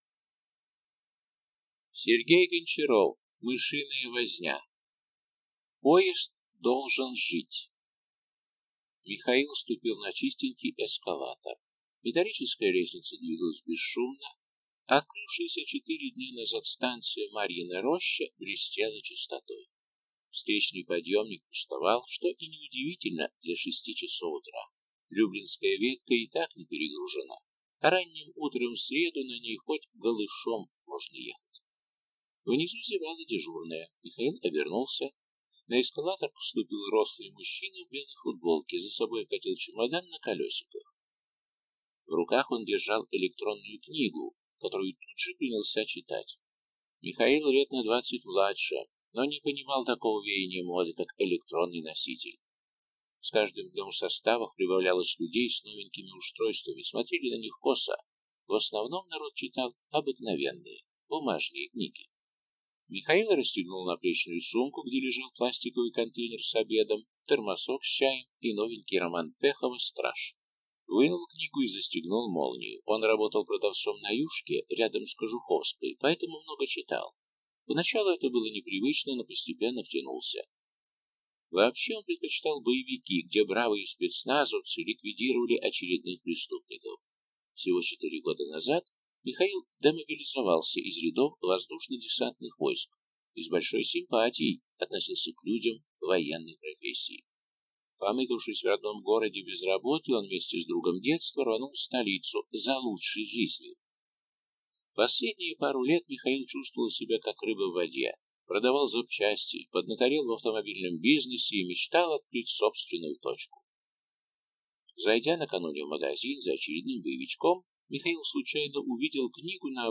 Аудиокнига Мышиная возня | Библиотека аудиокниг